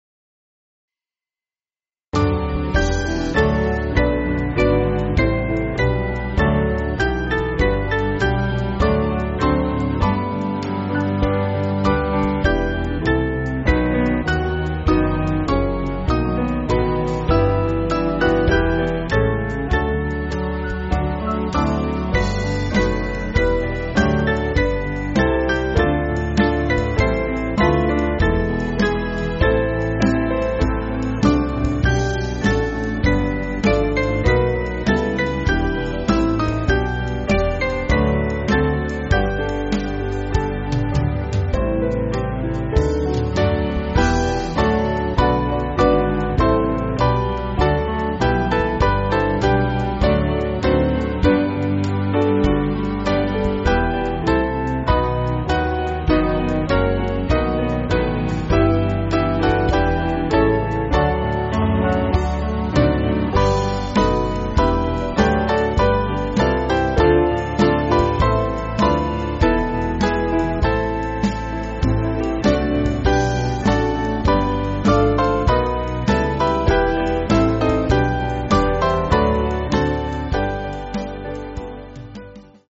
Small Band
(CM)   4/G